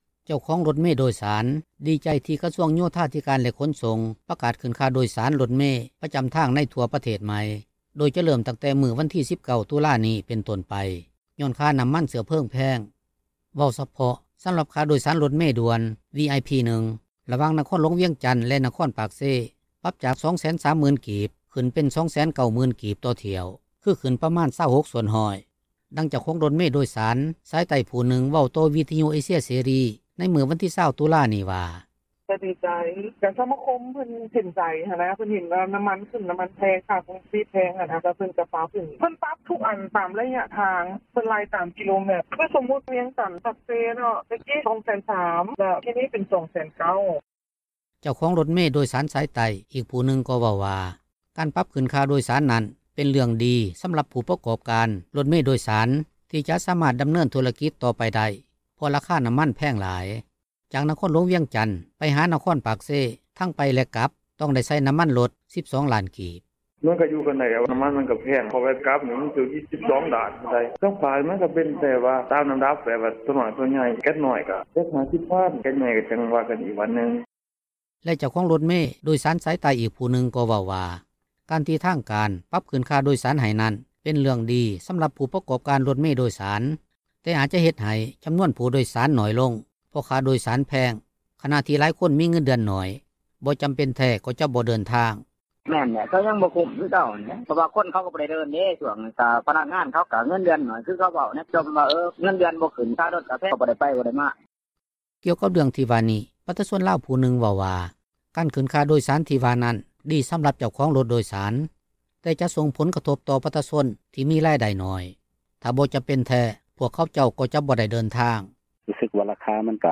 ດັ່ງເຈົ້າຂອງຣົຖເມ ໂດຍສານ ສາຍໃຕ້ຜູ້ນຶ່ງ ເວົ້າຕໍ່ວິທຍຸ ເອເຊັຽເສຣີ ໃນມື້ວັນທີ 20 ຕຸລານີ້ວ່າ: